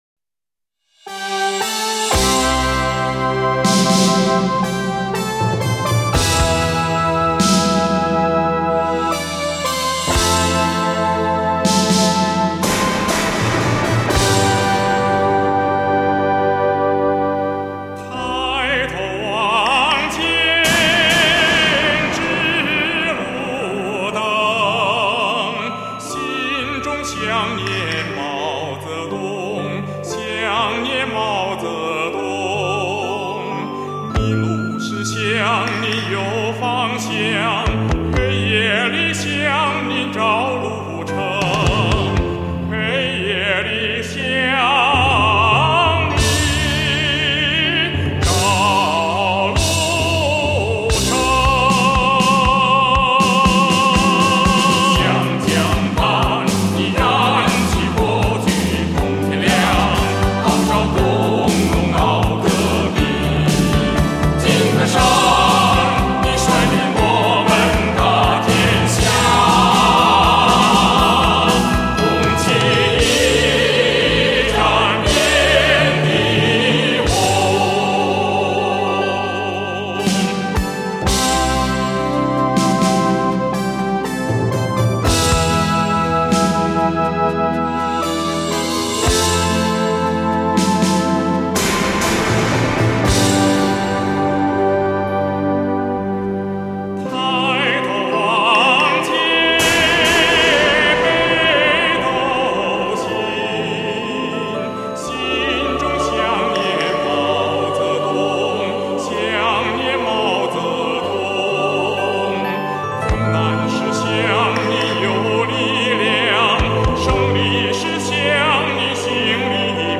独一无二的魅力唱腔，蕴味浓郁的地方民族风情。